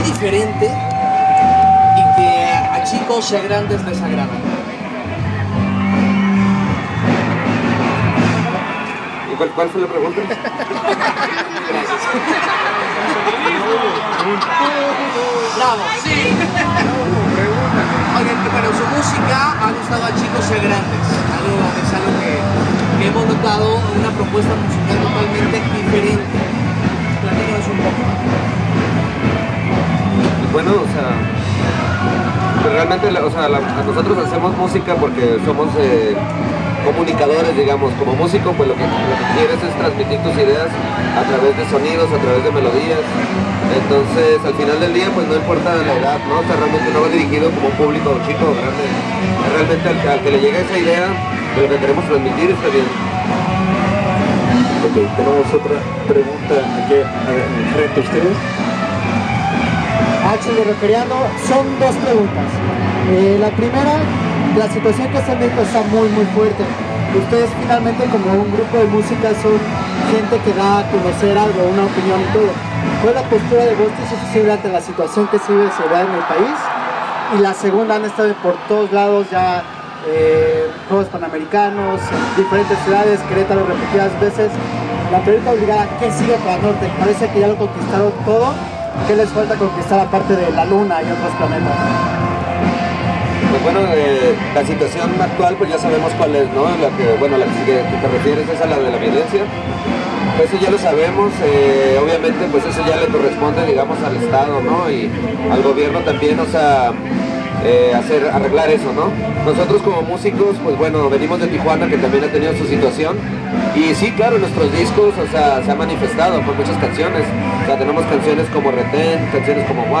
Entrevista con Nortec